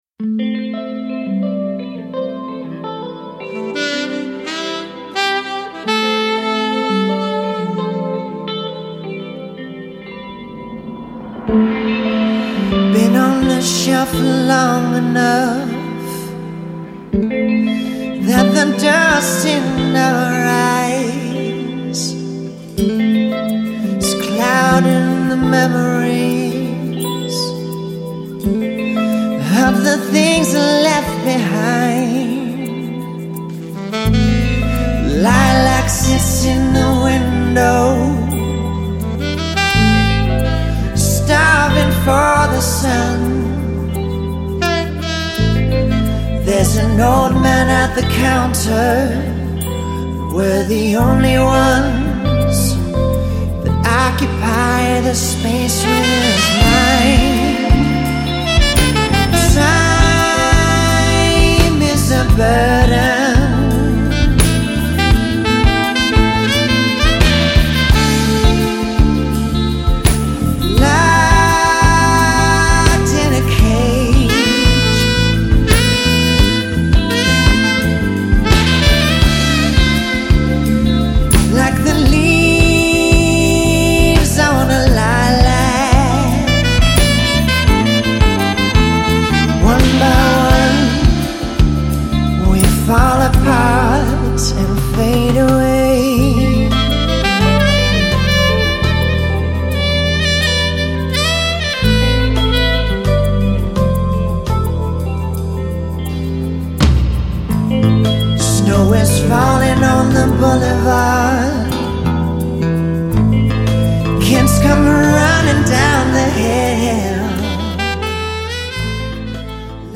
Adult Alternative
The songs are a mix of R&B, Adult Alternative and Neo Soul
a five piece horn section